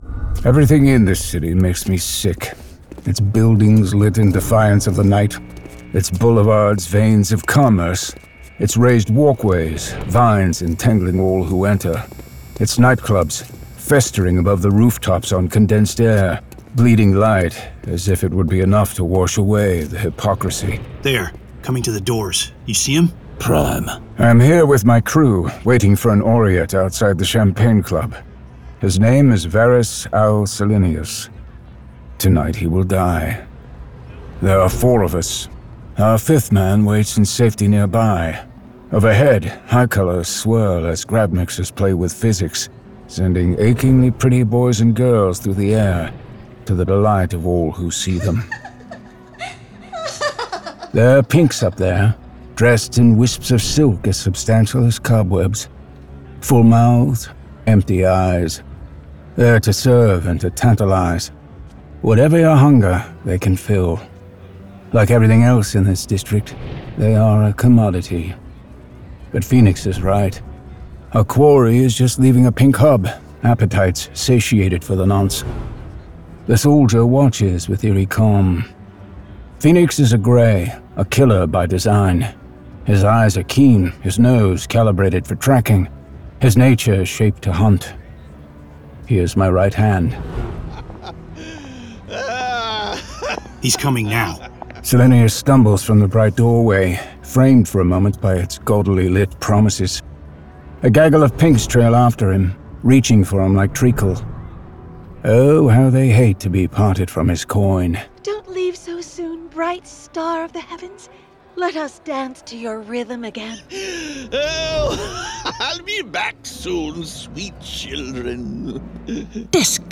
Full Cast. Cinematic Music. Sound Effects.
Adapted from the graphic novel and produced with a full cast of actors, immersive sound effects and cinematic music.